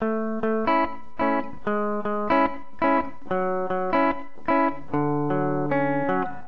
描述：寒冷的小吉他循环。
Tag: 74 bpm Chill Out Loops Guitar Electric Loops 1.09 MB wav Key : Unknown